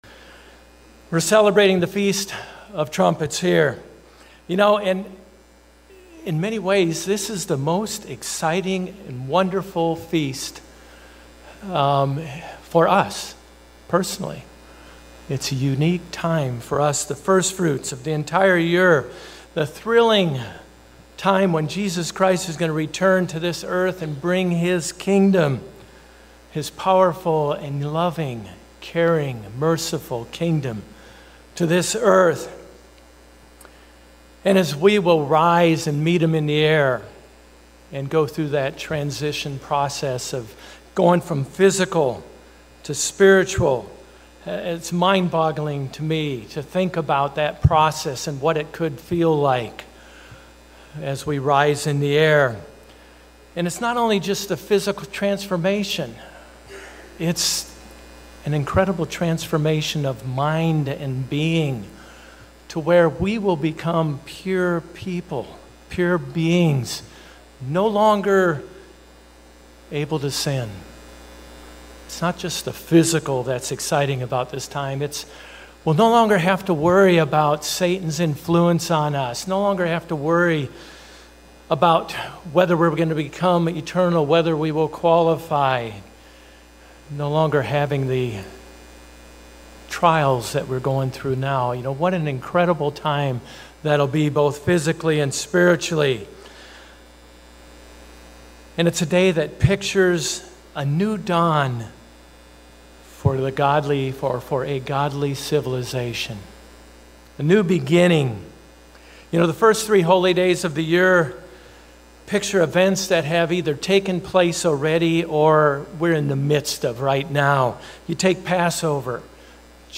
Given in Orlando, FL